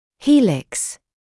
[‘hiːlɪks][‘хиːликс]спираль; завиток; завиток ушной раковины